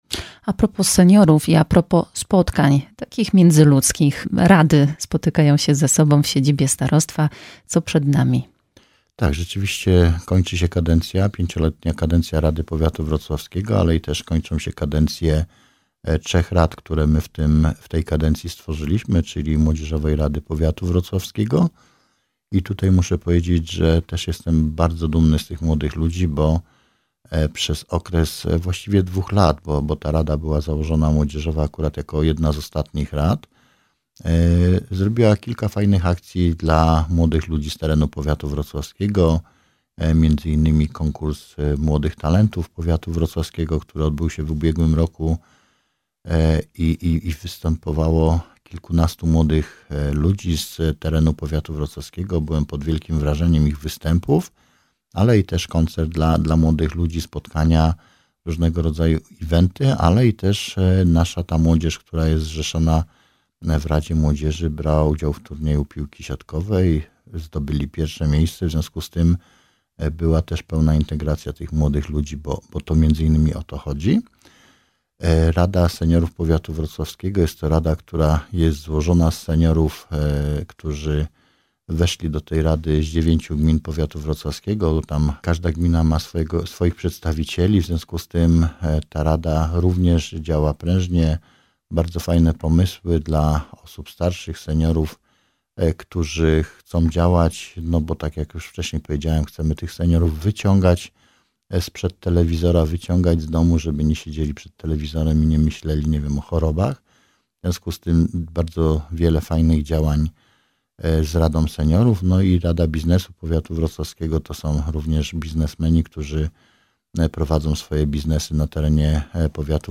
Naszym gościem jest Starosta Powiatu Wrocławskiego – Roman Potocki, z którym rozmawiamy o bieżących inwestycjach, działaniach edukacyjnych i profilaktyce w ramach polityki zdrowotnej.